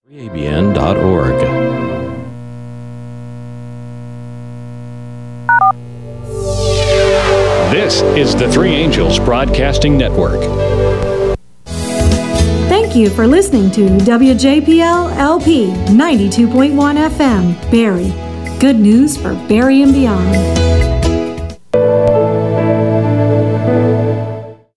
WJPL-LP Top of the Hour Audio: